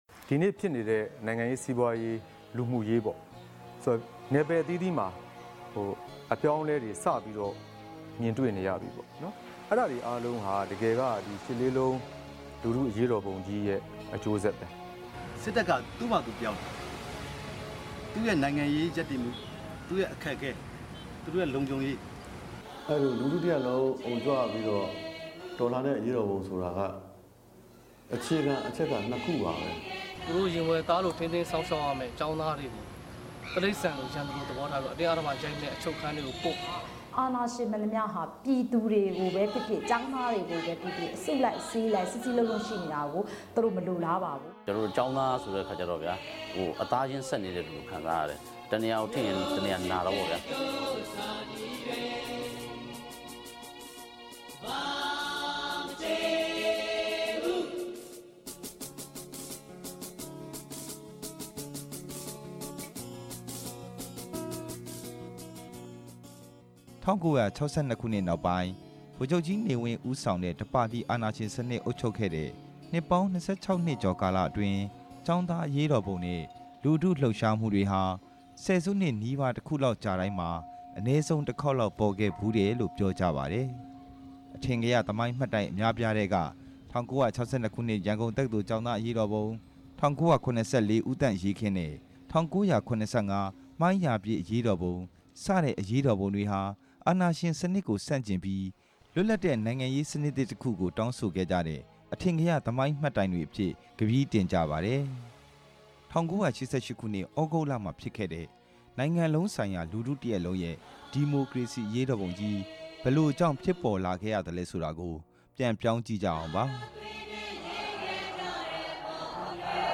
လွန်ခဲ့တဲ့ ၁၉၈၈ ခုနစ်က ရှစ်လေးလုံး ဒီမိုကရေစီ အရေးတော်ပုံကြီး ဘာကြောင့်ဖြစ်ပေါ်ခဲ့ရသလဲဆိုတာကို အဲဒီအချိန်က ဦးဆောင်ပါဝင်ခဲ့ကြတဲ့ ကျောင်းသားခေါင်းဆောင်တွေ အပါအဝင် ထင်ရှားတဲ့လူပုဂ္ဂိုလ်တချို့ကို မေးမြန်းတင်ပြထားပါတယ်။